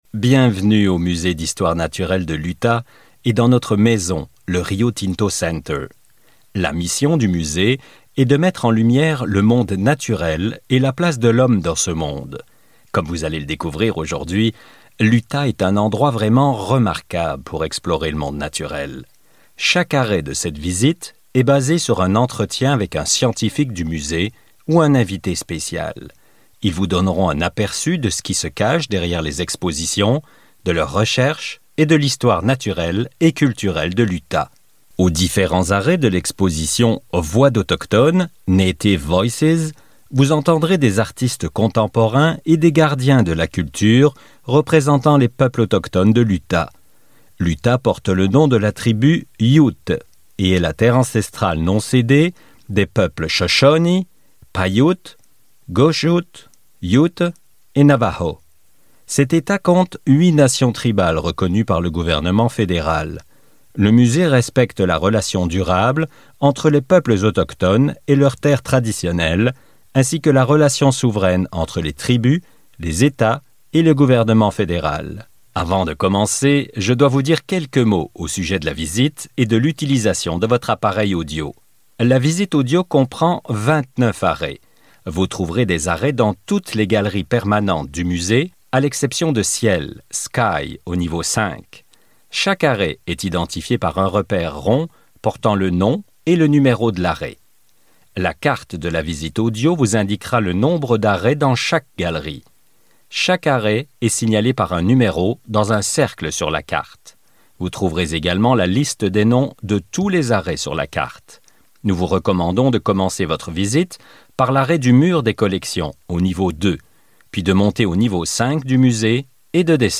Voice Samples: Museum Visit
male